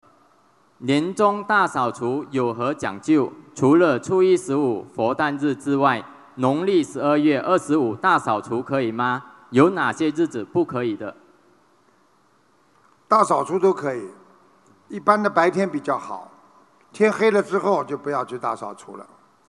2018年12月28日马来西亚•吉隆坡世界佛友见面会提问